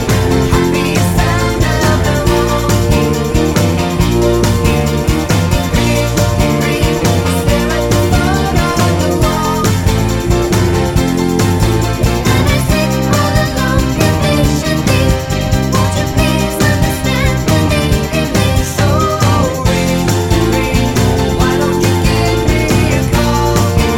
One Semitone Up Pop (1970s) 3:02 Buy £1.50